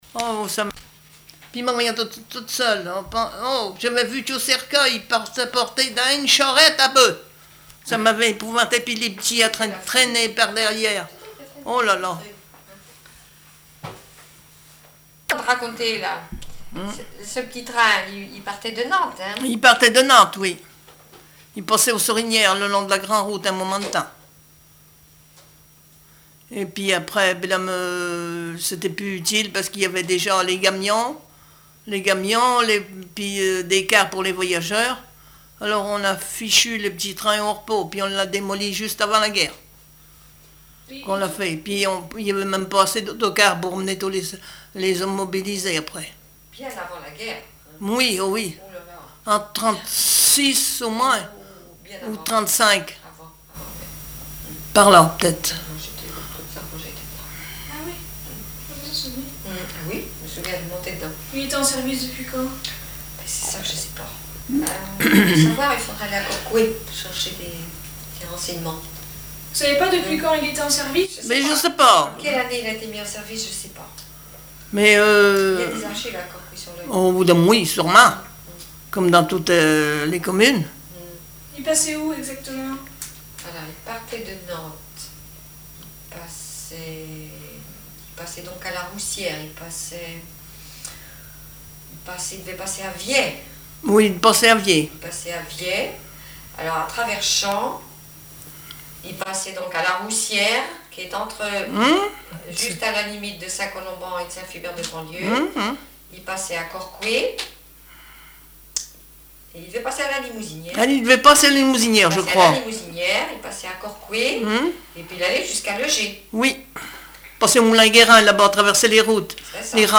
Témoignages et chansons
Catégorie Témoignage